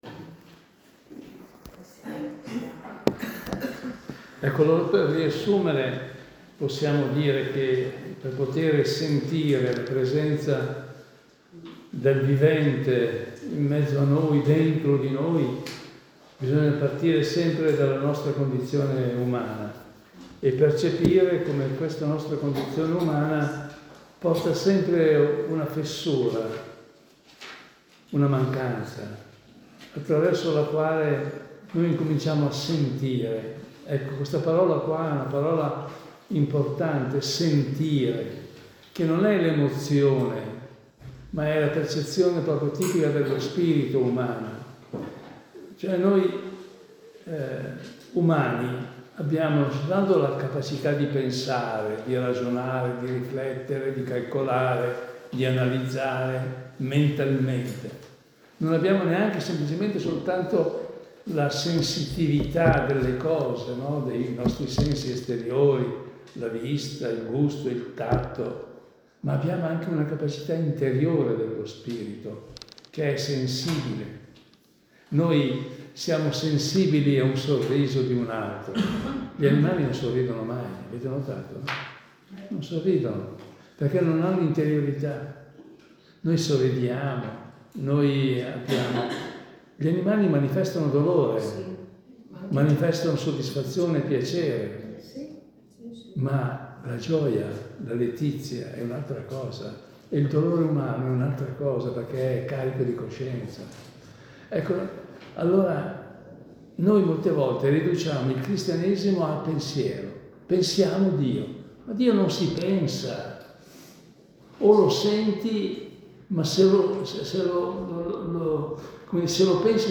Ascolta l’audio della seconda parte della catechesi: